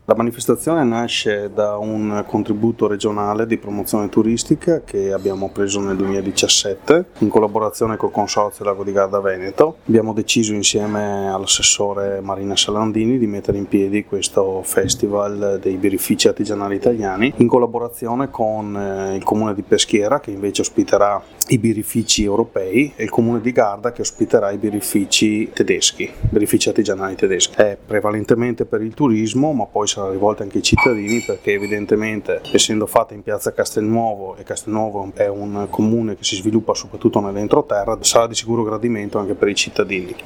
Al nostro microfono anche Davide Sandrini, assessore al Turismo di Castelnuovo